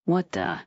Add Juni Mission Voice Files